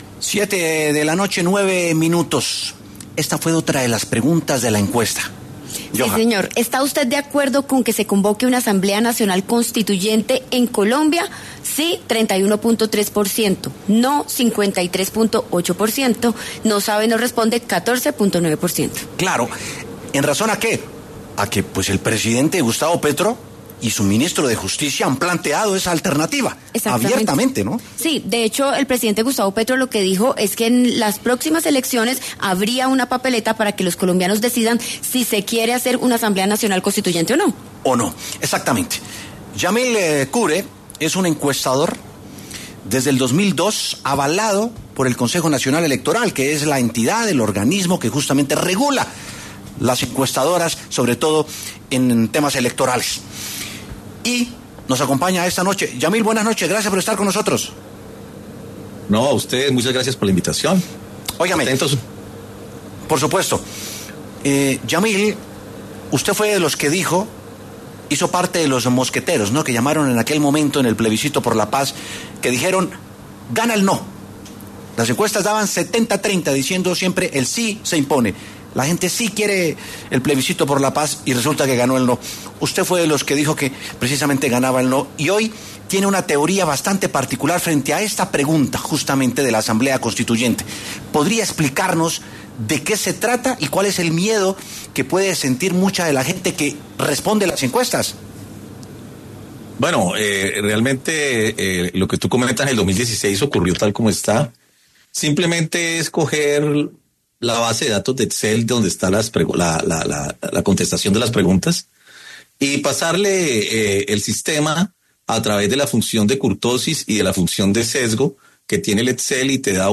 W Sin Carreta habló con un encuestador y analista, quien aseguró que si Miguel Uribe no es el candidato de la derecha colombiana, ese espectro político se “fragmentaría”.